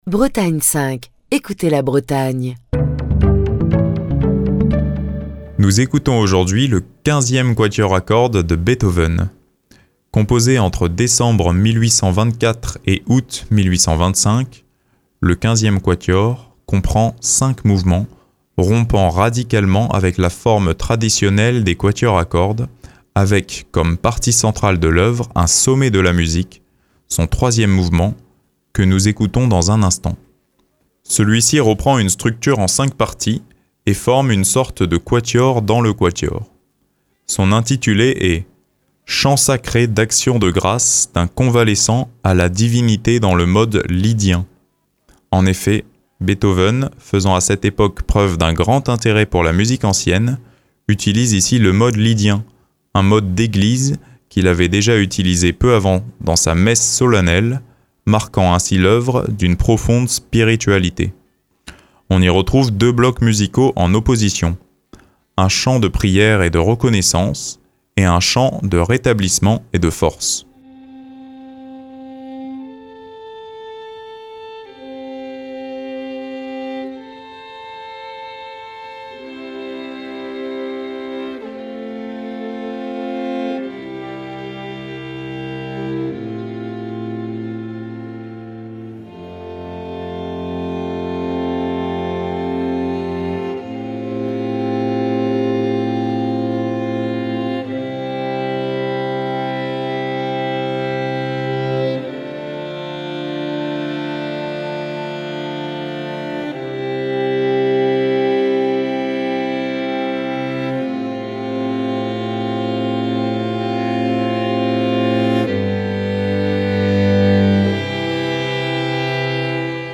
On y retrouve deux blocs musicaux en opposition : un chant de prière et de reconnaissance, et un chant de rétablissement et de force. Voici le très mystique troisième mouvement du Quinzième Quatuor de Beethoven, interprété par le Quatuor Ébène.